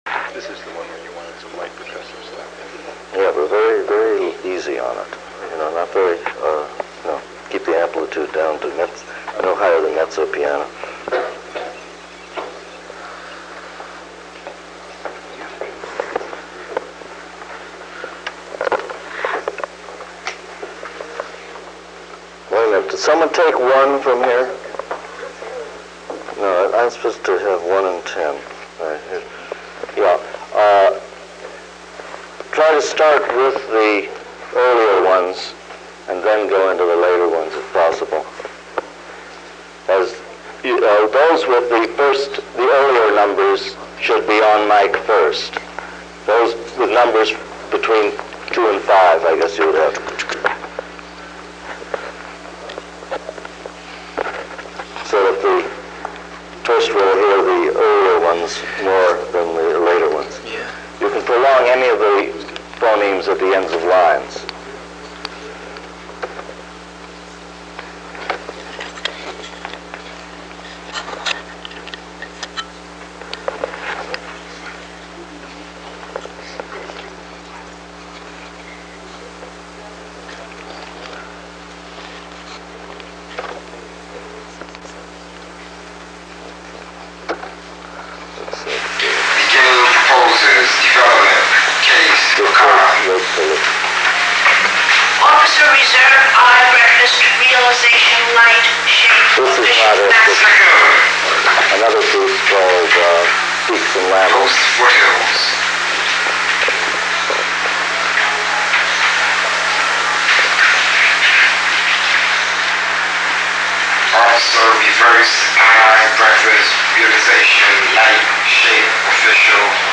Production Context: Documentary recording